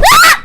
ow3.wav